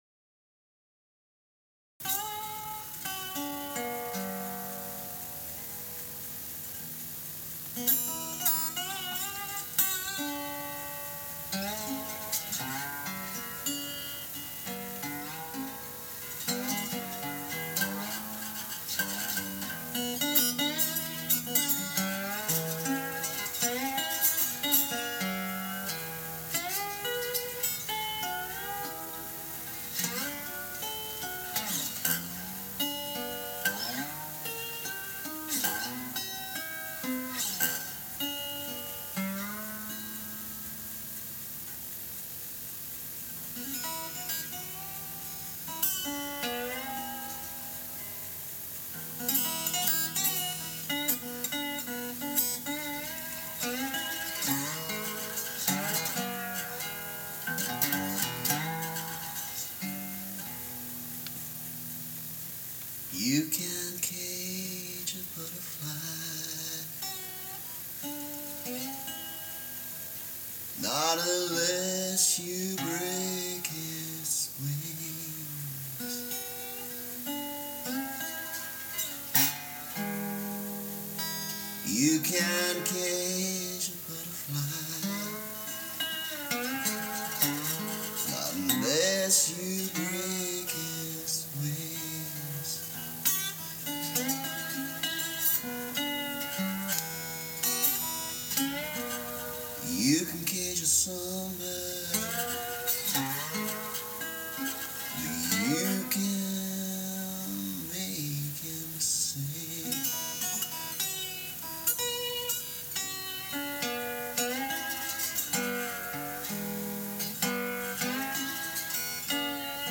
Essentially a 12- bar but with more minor chording than you might expect.
And a slide version. Too slow for my taste now, but some nice slide-y moments.